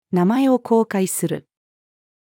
名前を公開する。-female.mp3